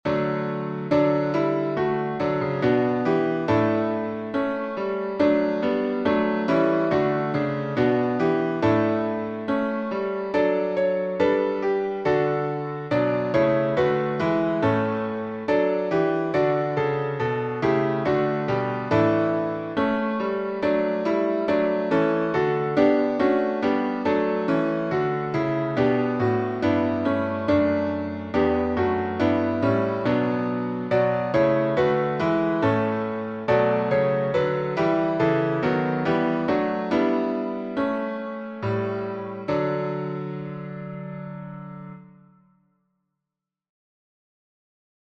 #6040: All Creatures of Our God and King — D major | Mobile Hymns